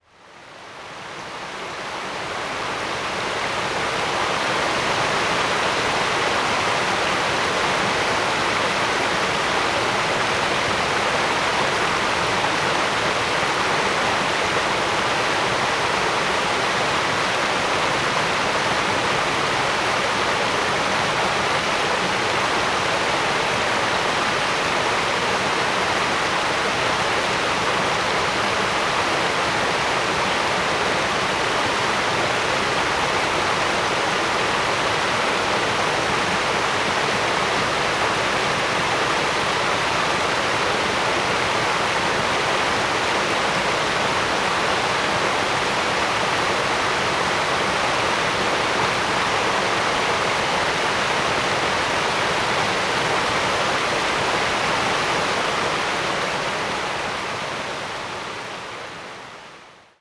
Recorded these four soundscapes during a walk on the Copley Ridge and Knarston Creek Loop trail in the hills above Lantzville on December 6, 2021.
2. Knarston Creek above the waterfall, about 12 feet from creek